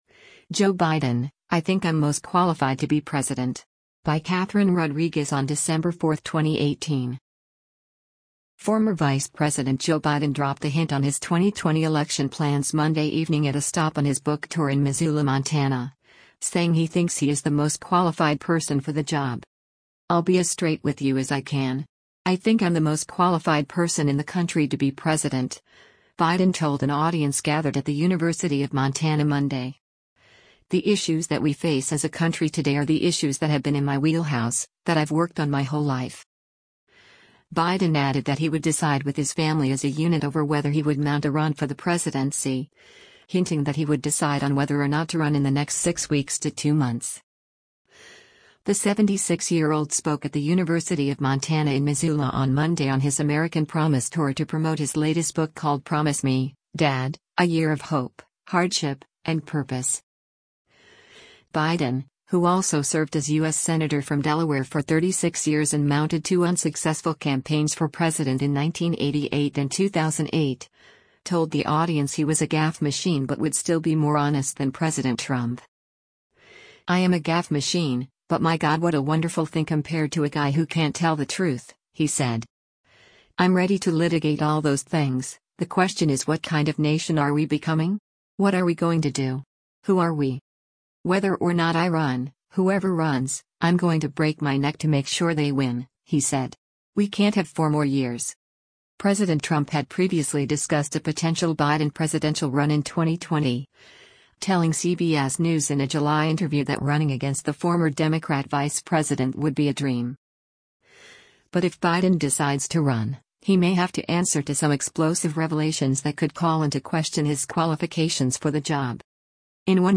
The 76-year-old spoke at the University of Montana in Missoula on Monday on his “American Promise Tour” to promote his latest book called Promise Me, Dad: A Year of Hope, Hardship, and Purpose.